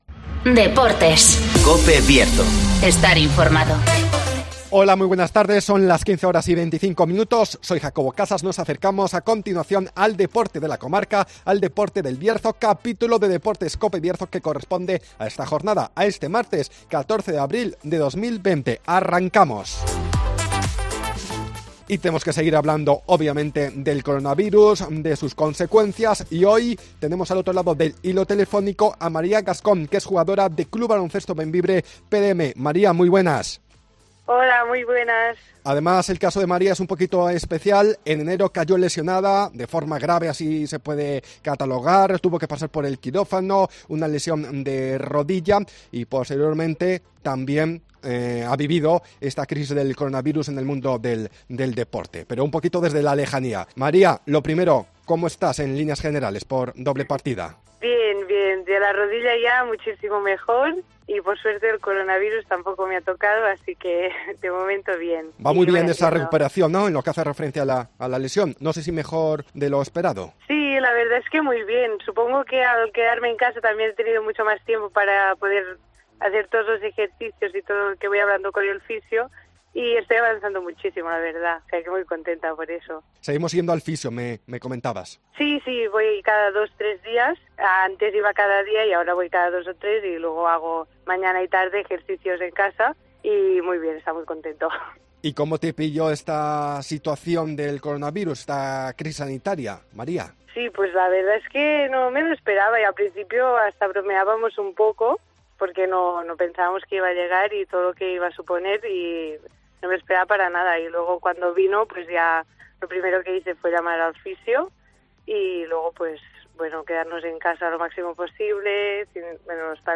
-Crisis del coronavirus -Entrevista